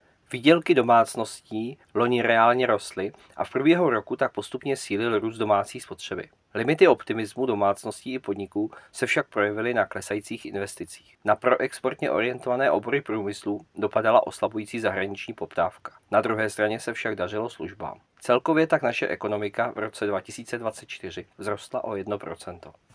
Vyjádření Jaroslava Sixty, místopředsedy Českého statistického úřadu, soubor ve formátu MP3, 899.06 kB